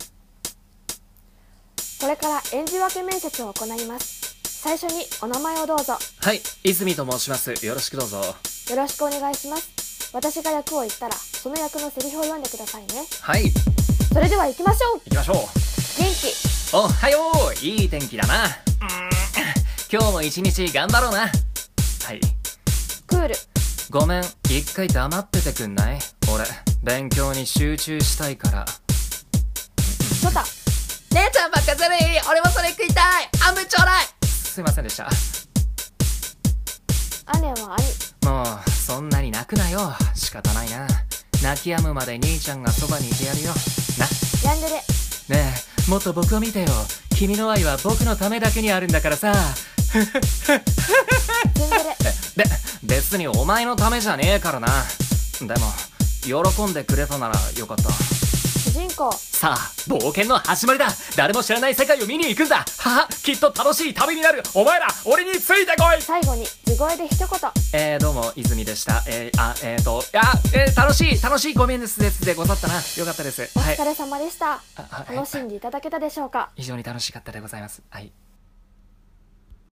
7役演じ分け声面接!!